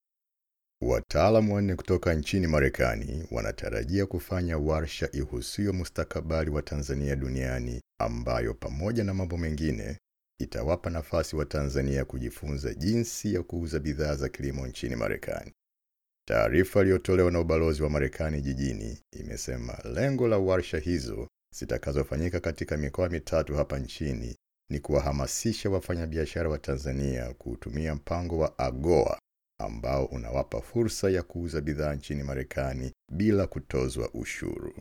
Experienced voice over talent with a baritone, calm, easy listening, "the guy next door" voice.
Sprechprobe: Sonstiges (Muttersprache):